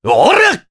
Mitra-Vox_Attack3_jp.wav